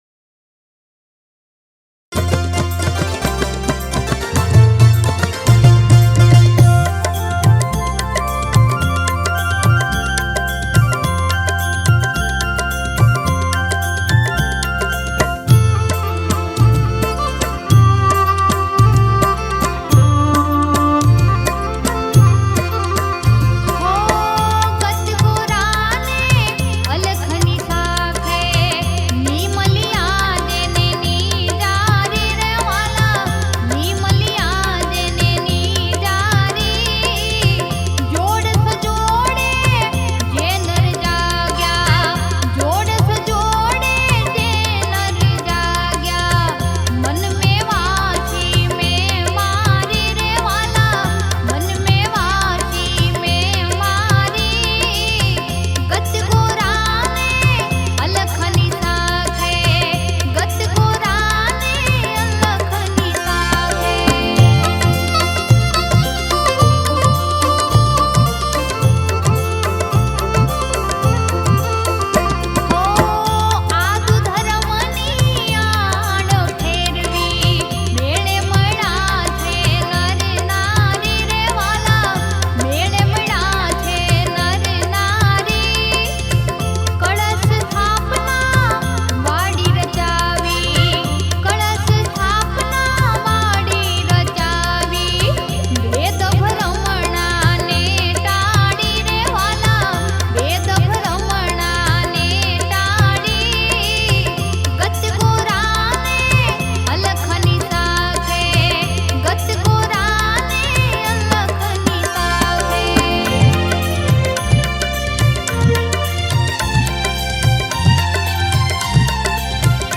Gujarati Bhajan
Ramdevji Bhajan